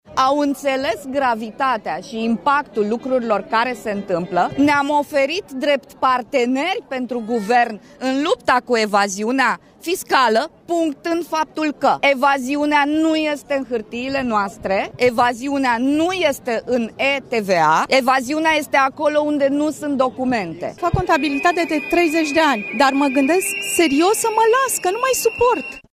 „Au înțeles gravitatea și impactul lucrurilor care se întâmplă. Ne-am oferit drept parteneri pentru Guvern în lupta cu evaziunea fiscală punctând faptul că: evaziunea nu este în hârtiile noastre, evaziunea nu este în e-TVA. Evaziunea este acolo unde nu sunt documente”, a spus o femeie.
„Fac contabilitate de 30 de ani, dar mă gândesc serios să mă las că nu mai suport”, a spus o altă femeie.
08iul-13-VOX-contabili.mp3